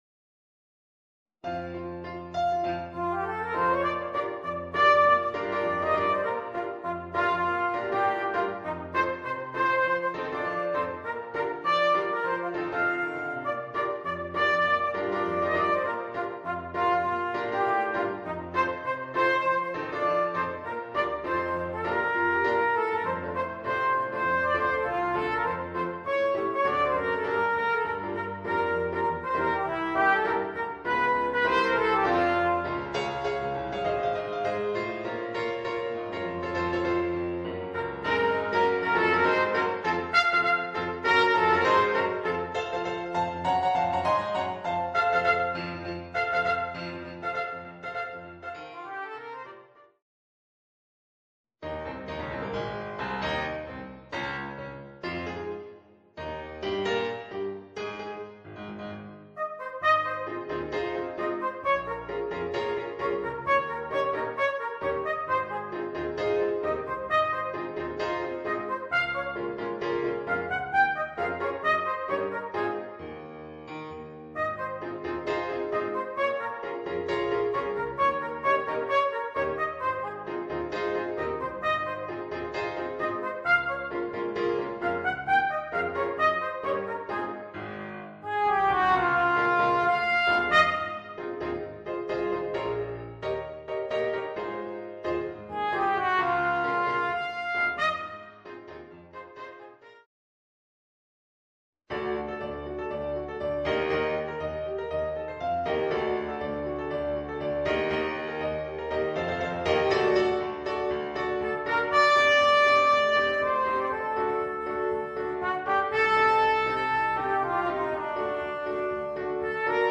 Trumpet and Piano